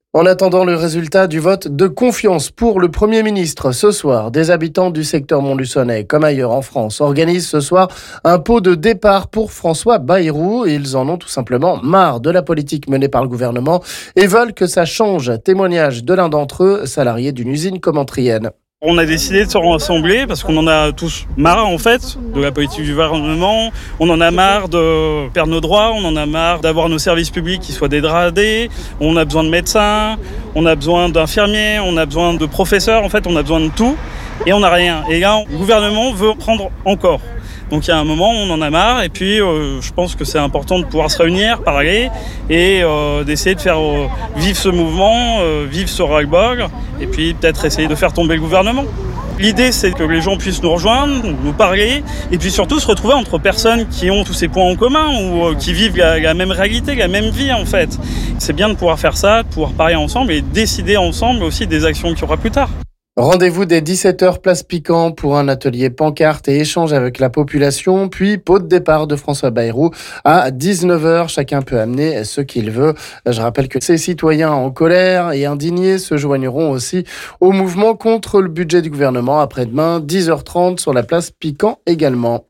On écoute l'un d'entre eux...